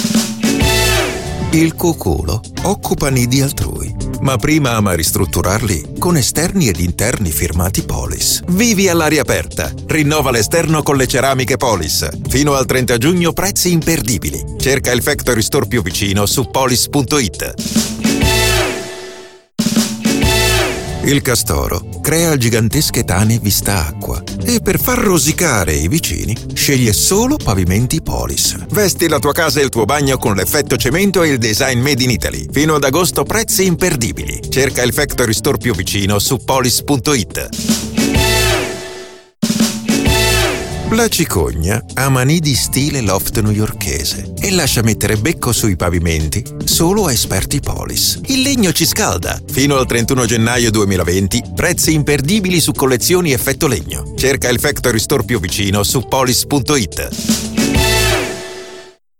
Male
Adult (30-50)
My voice is warm and sensual